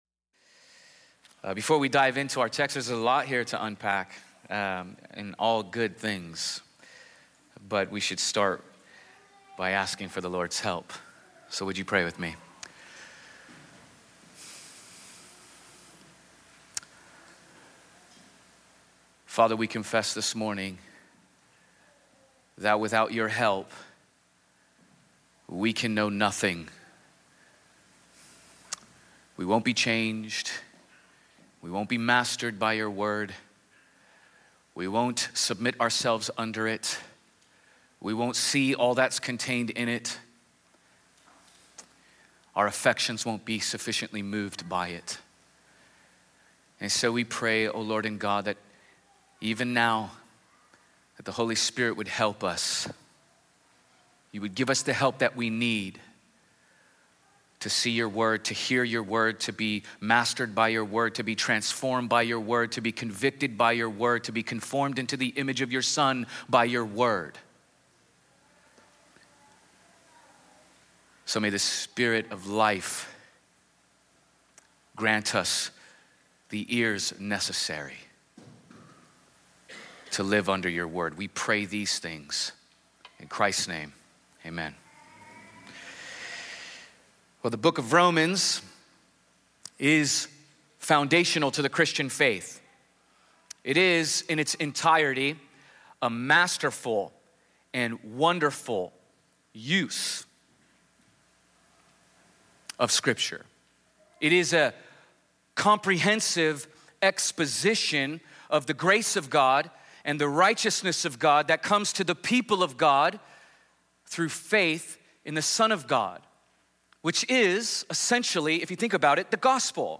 This sermon starts with a brief overview of the first seven chapters of Romans. We then learned that because of what God has done for people who are in Christ we will never suffer condemnation.